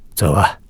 序章与第一章配音资产
c02_4瞎子_11.wav